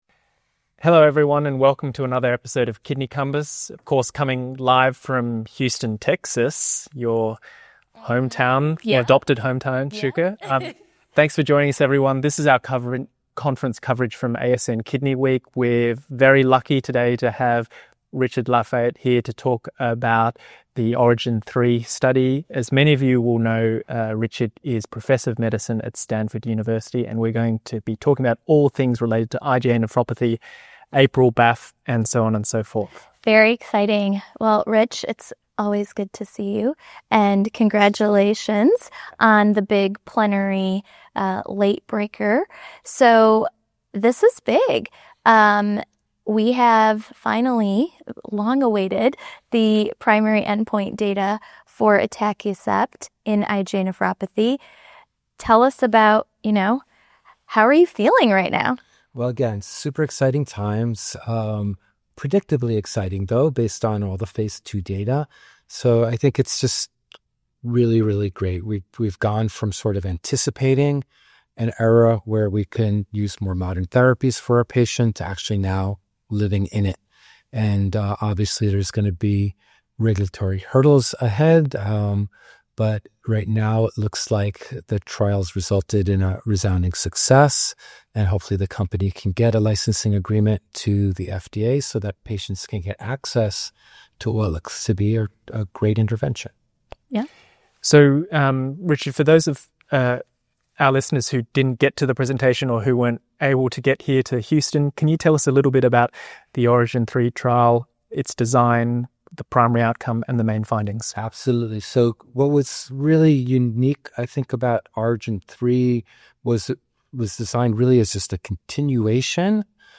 from the floor at the American Society of Nephrology (ASN) Kidney Week 2025